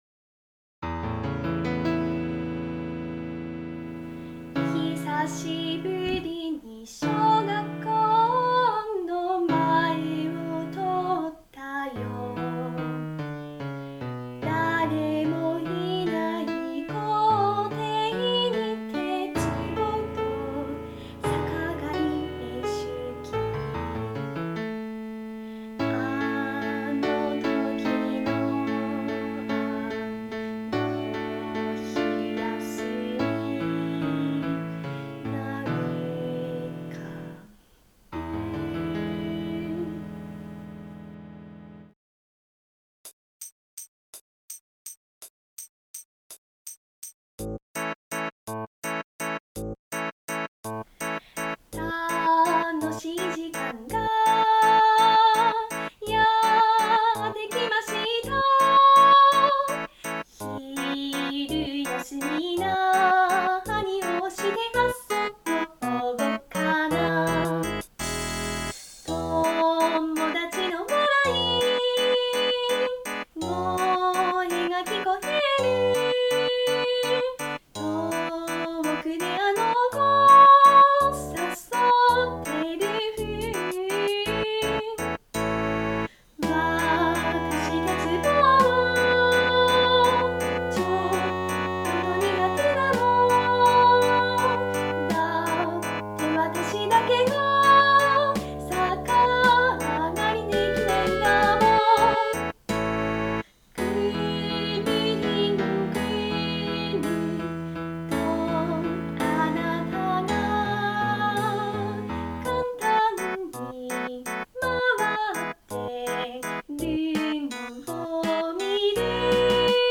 ※自作のJingleを入れました。
※オリジナルではラストフレーズのリピートは有りません。
※この楽曲の参考演奏は「低声用」です。